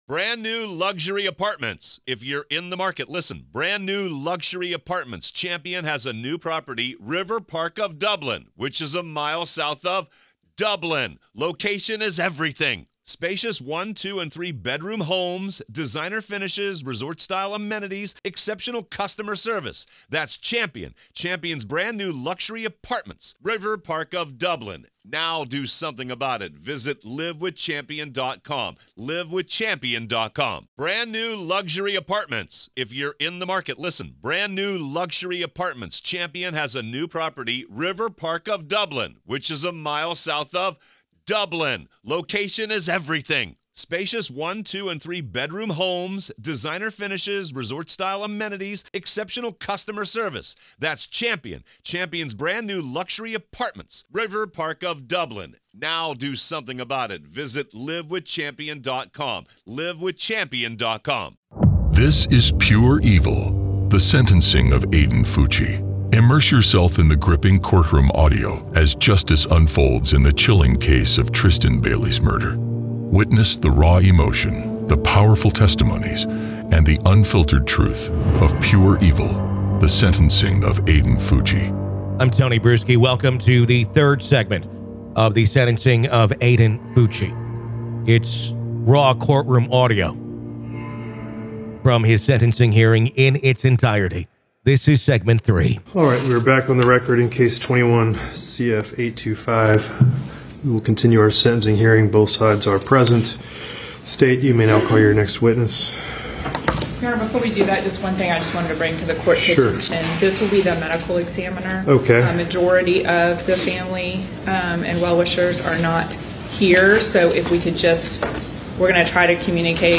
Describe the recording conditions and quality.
This gripping podcast series transports you to the heart of the legal proceedings, providing exclusive access to the in-court audio as the prosecution and defense lay out their arguments, witnesses testify, and emotions run high.